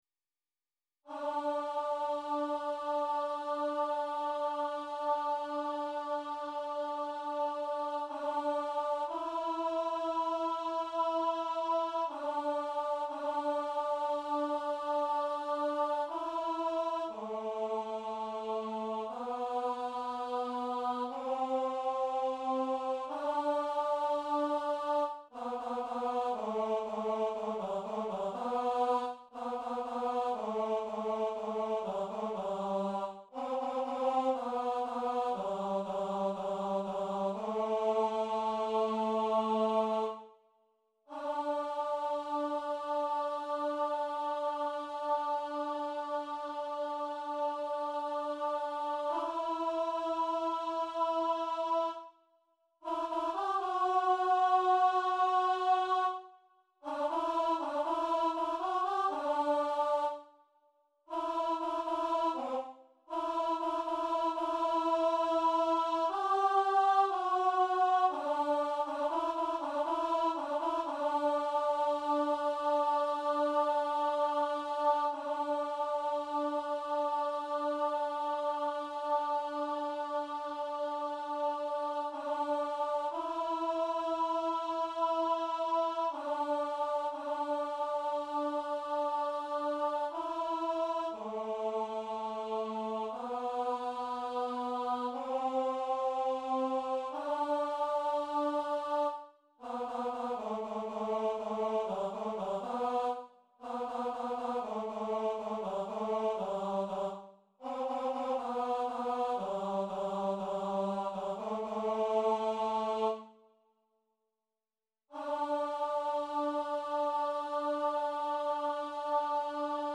Mp3 file with MIDI sound.
SATB chorus